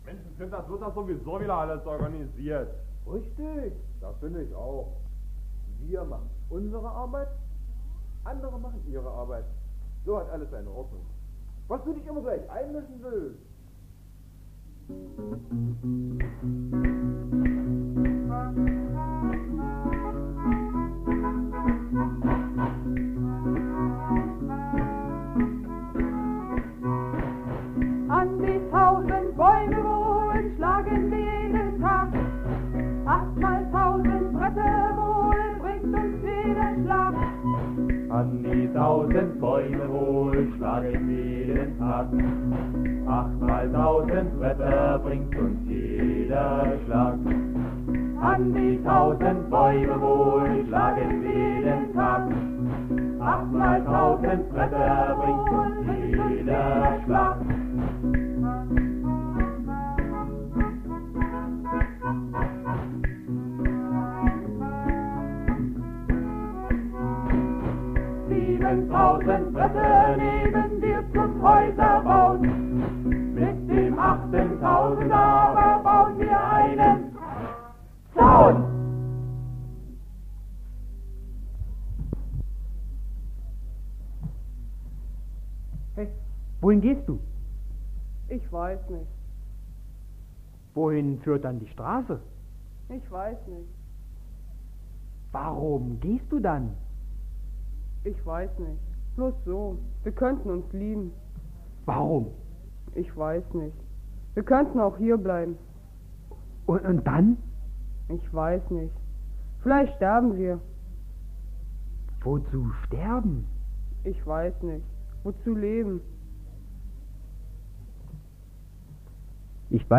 Gesang, Gitarre
Gesang, Akkordeon